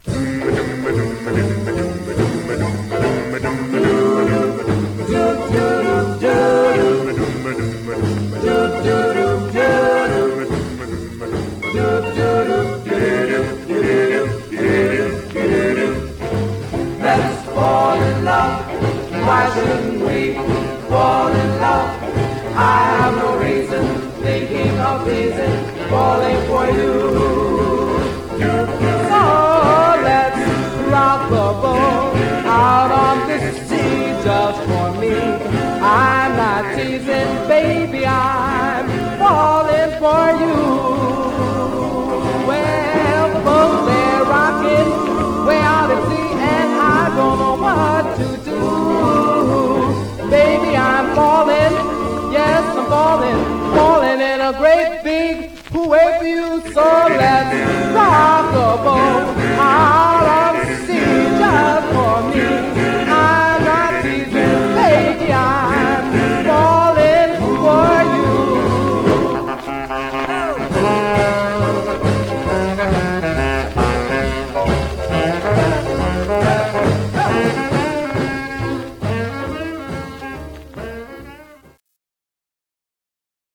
Much surface noise/wear
Mono
Male Black Groups